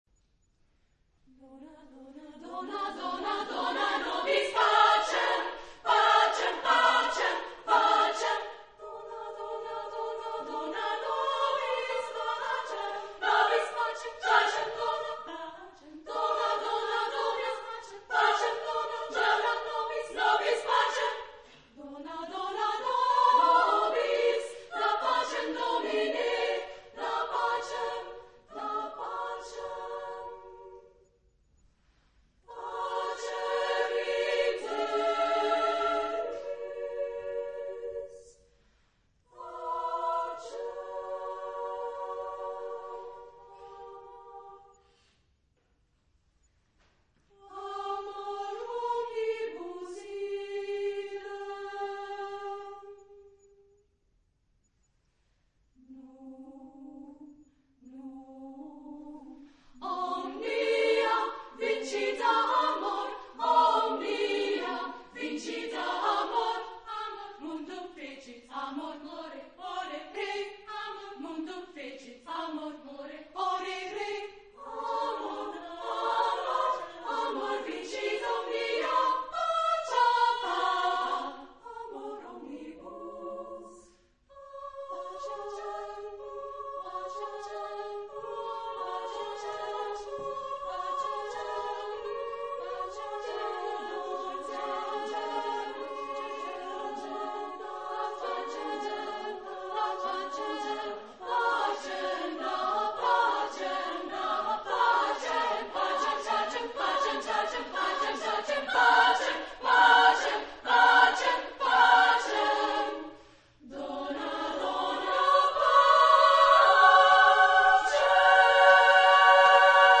Genre-Style-Forme : Liturgie ; Prière ; chant sacré ; Sacré
Type de choeur : SMA  (3 voix égales )
Solistes : Alto (1) OU Soprano (1)  (2 soliste(s))
Tonalité : diverses